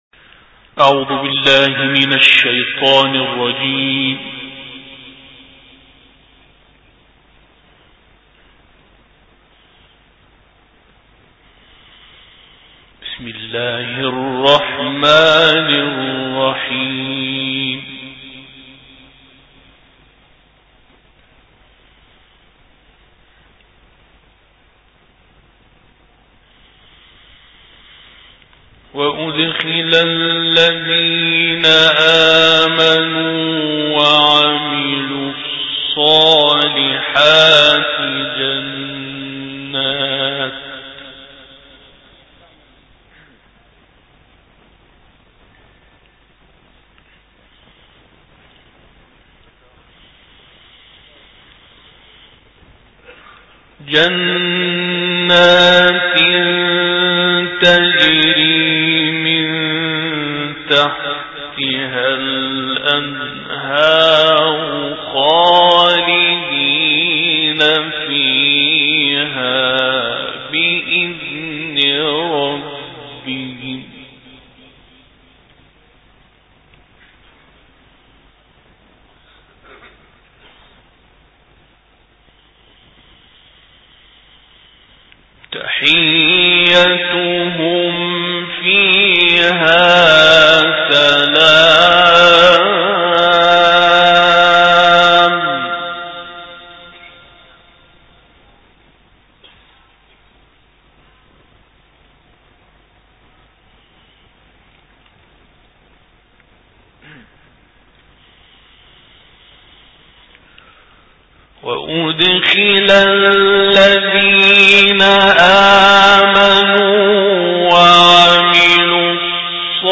تلاوت آیاتی از سوره ابراهیم
تلاوت آیاتی از سوره مبارکه ابراهیم